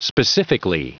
Prononciation du mot specifically en anglais (fichier audio)
Prononciation du mot : specifically
specifically.wav